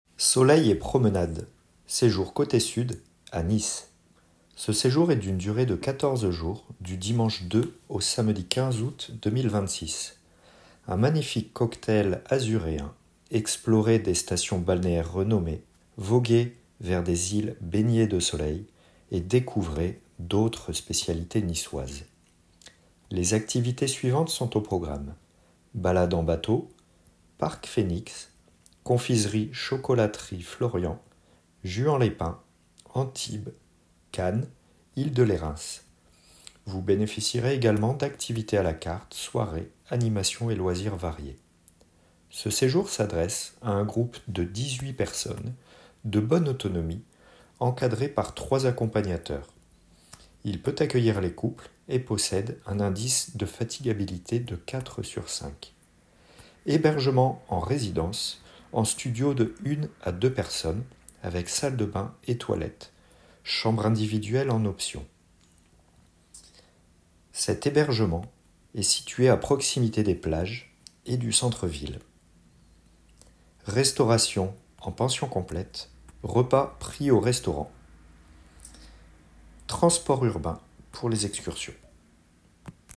Présentation audio du séjour